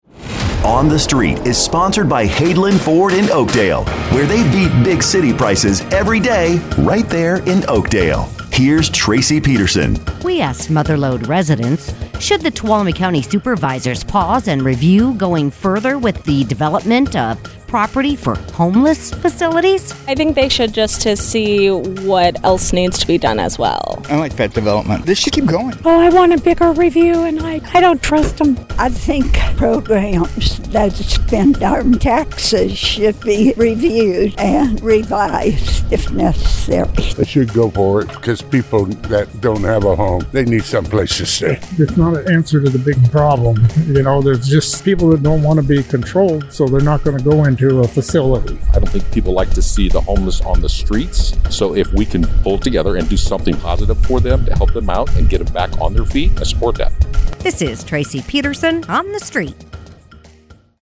asks Mother Lode residents